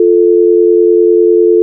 dialtone.wav